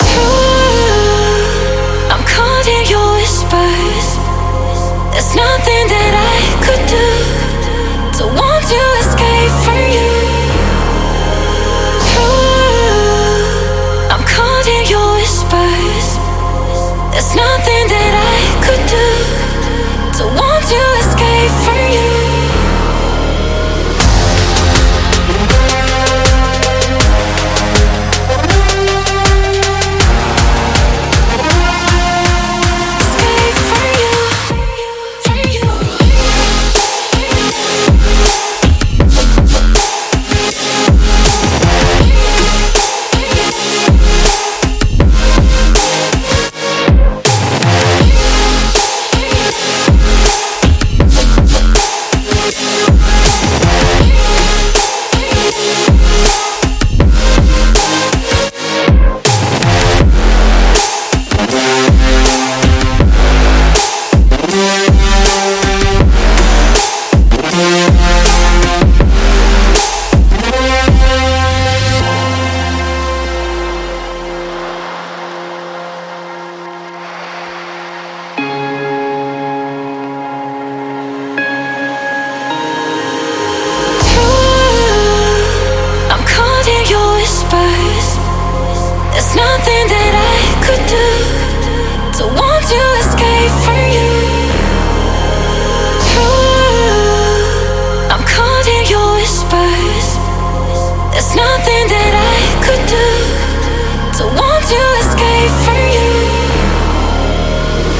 这款史诗级Future Bass采样包原价25美元现在只需要10美元